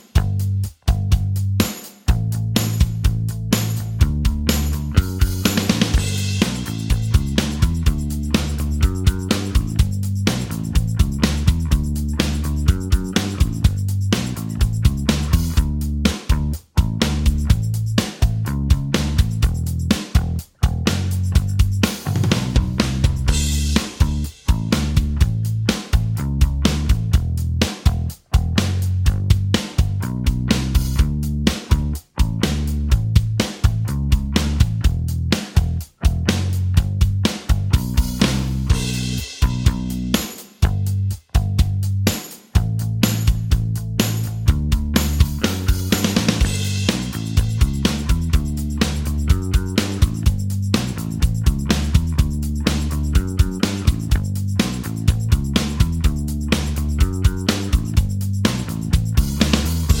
Minus Main Guitars For Guitarists 3:27 Buy £1.50